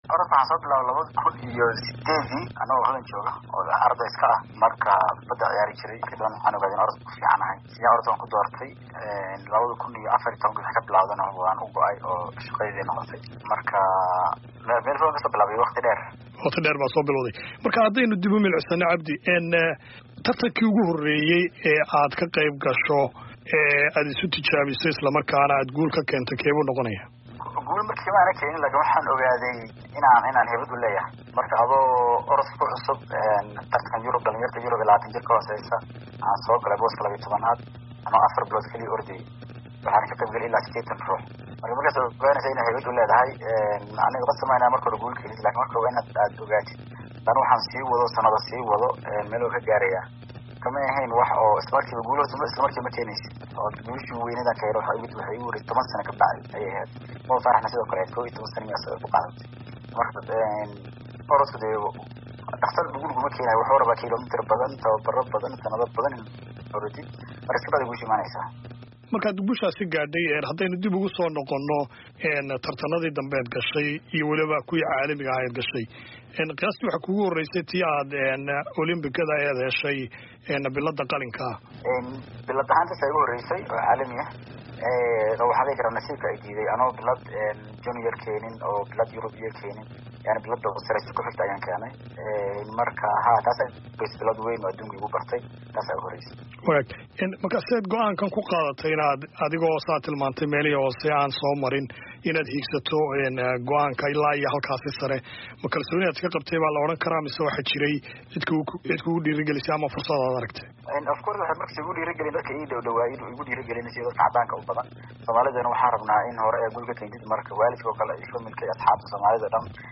Wareysi: Muxuu orodyahan Cabdi Nageeye ka yiri guulihii uu gaaray?